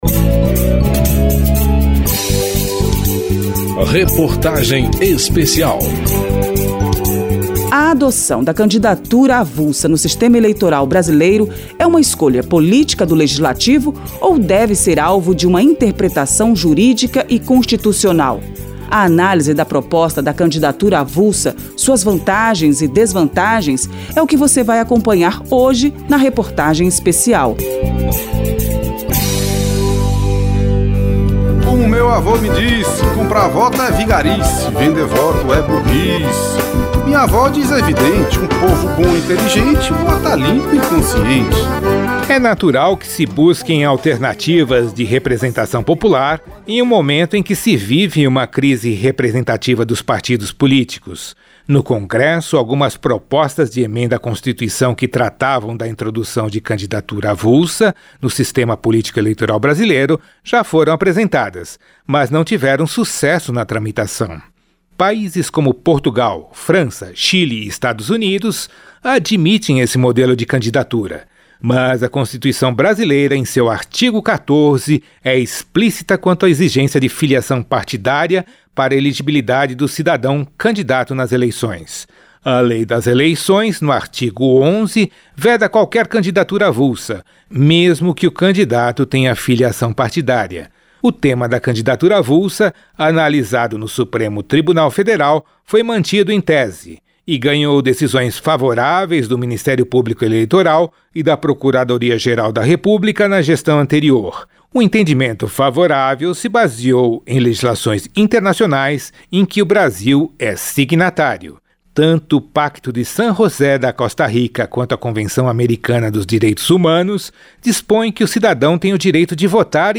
Reportagem Especial
Nesta quarta reportagem, um deputado e uma especialista em direito eleitoral se posicionam de forma divergente na análise sobre uma possível introdução do modelo de candidatura avulsa no país. E se essa mudança seria benéfica ou prejudicial à sobrevivência dos partidos políticos.